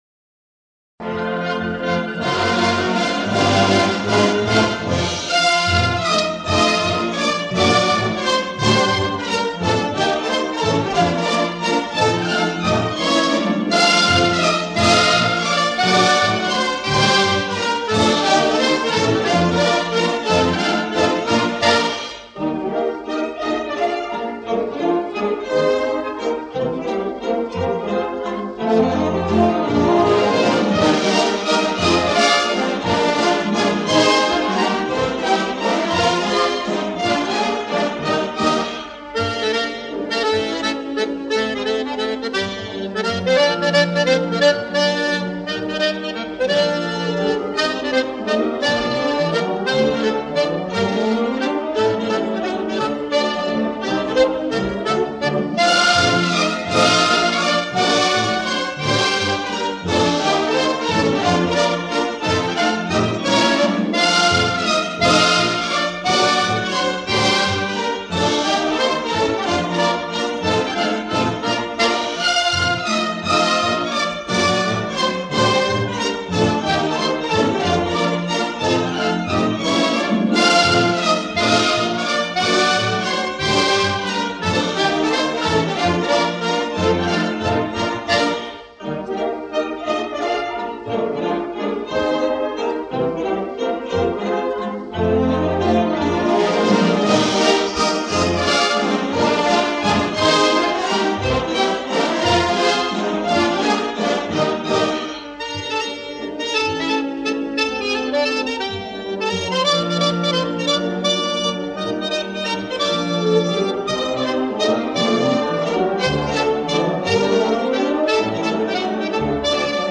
Звук нормализован но пикам, а не по средней громкости.